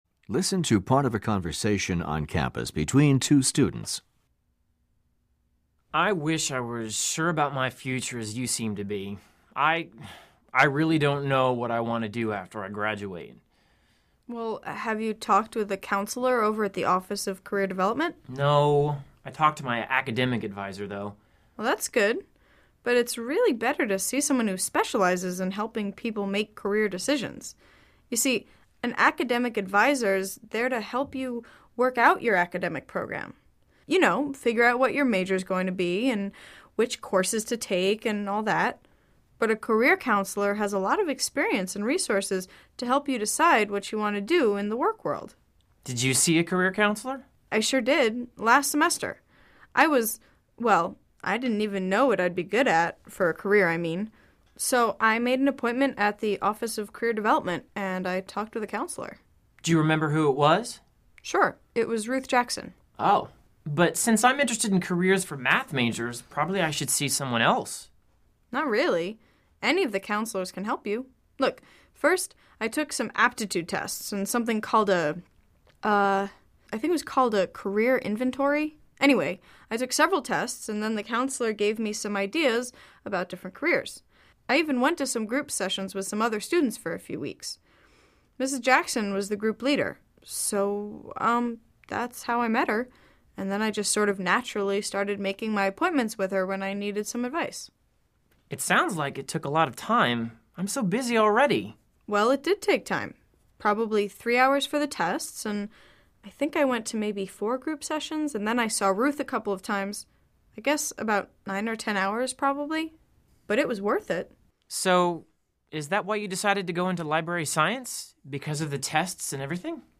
Directions: This section measures your ability to understand conversations and lectures in English.